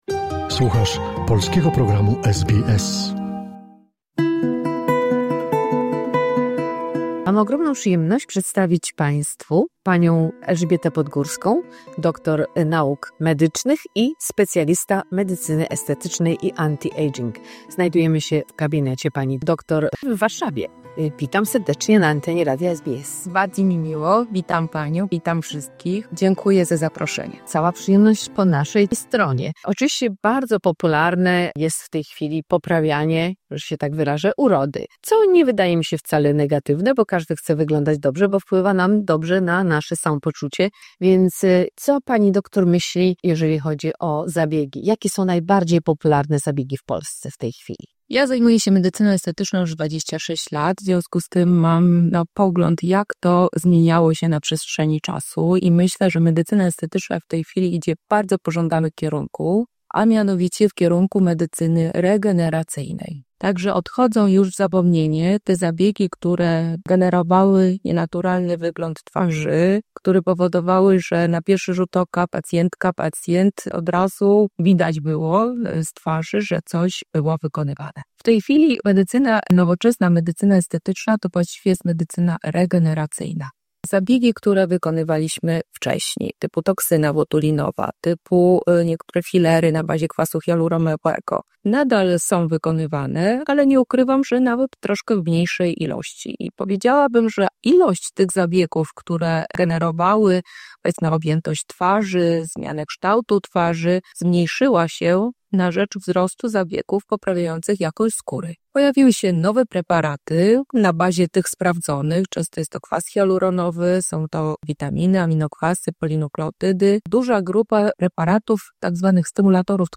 We would like to inform you that the information expressed in this interview is of a general nature.